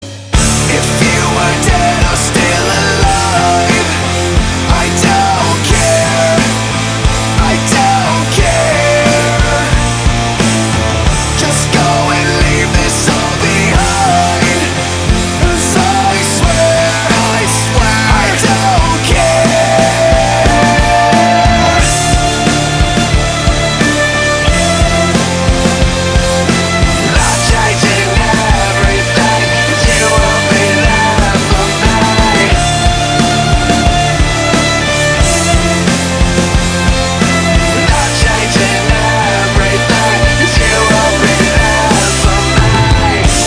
• Качество: 320, Stereo
громкие
Alternative Metal
post-grunge
Progressive Metal
Progressive rock